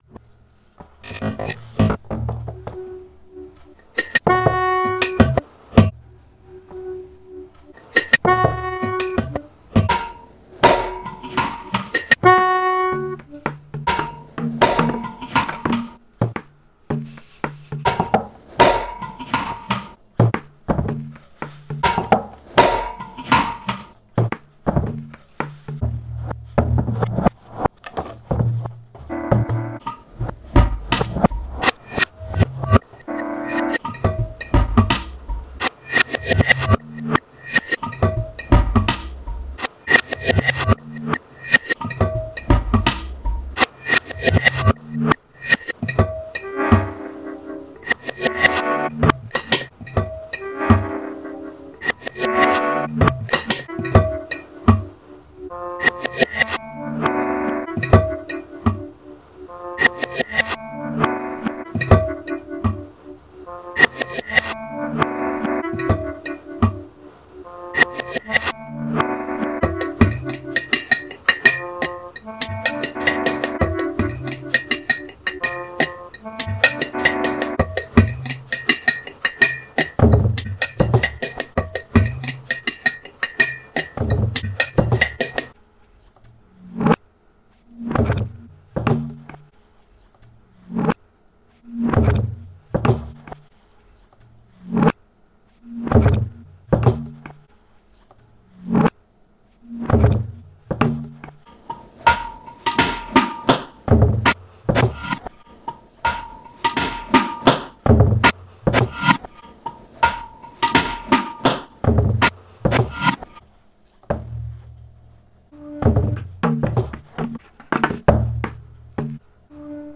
[Pianica,OtonohaVase,UmeTom]
Csound(overtones+roots)